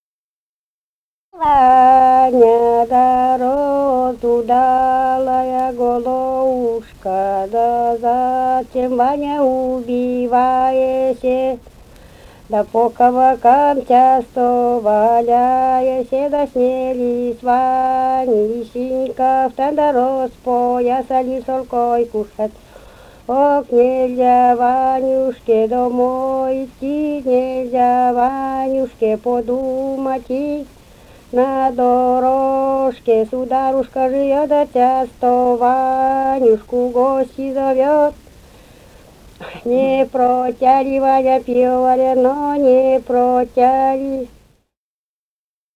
«Ваня, разудалая голова» (лирическая).